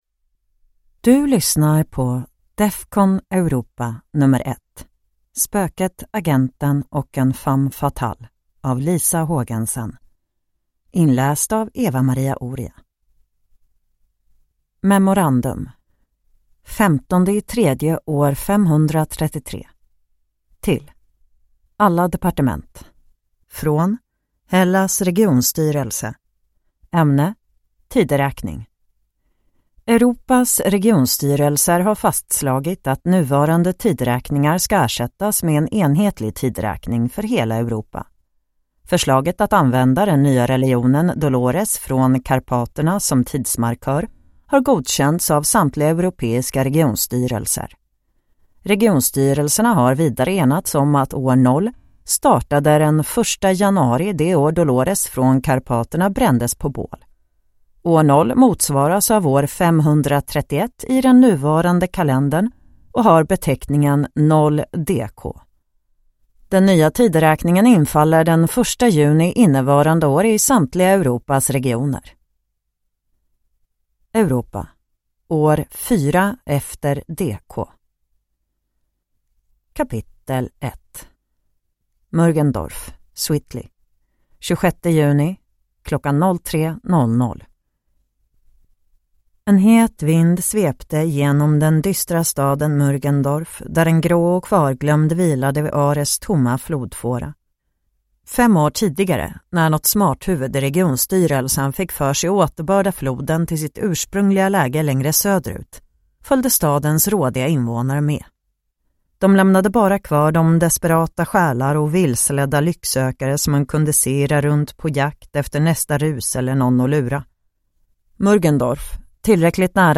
Defcon Europa #1: Spöket Agenten & En Femme Fatale – Ljudbok – Laddas ner